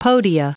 Prononciation du mot podia en anglais (fichier audio)